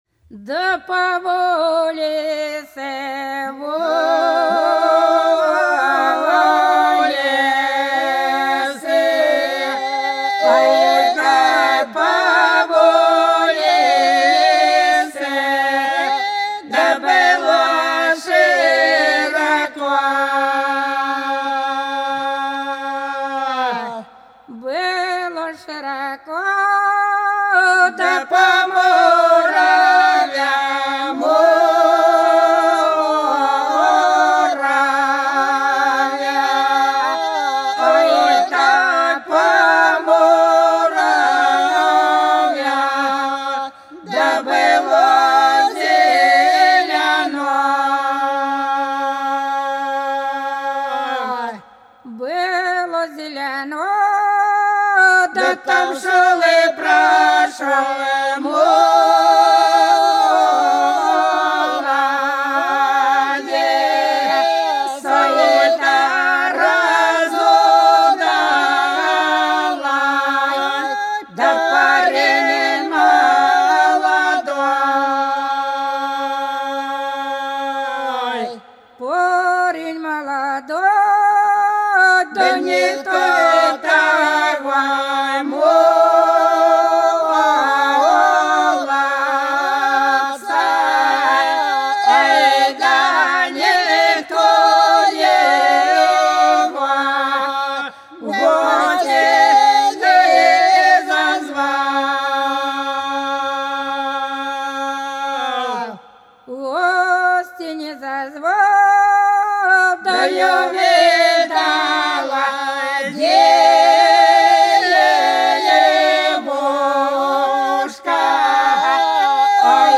По-над садом, садом дорожка лежала Да по улице, улице - протяжная (с.Плёхово, Курской области)